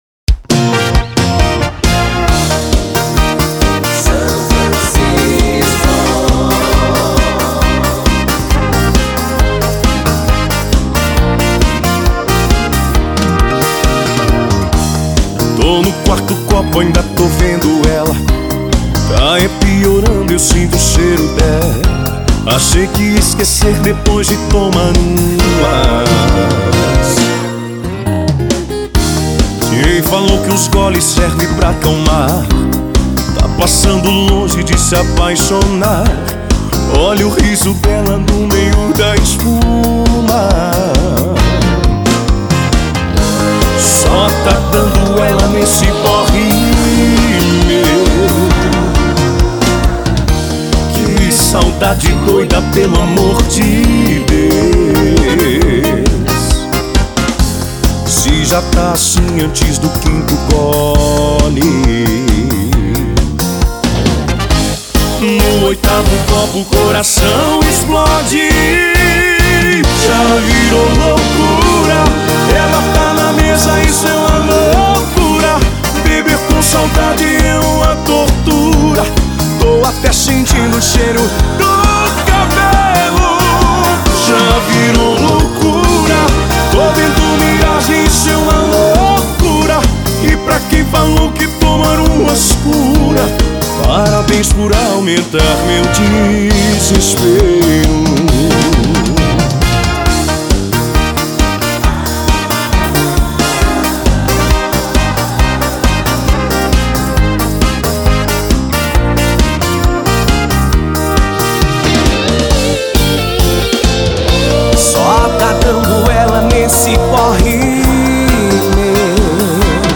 EstiloRegional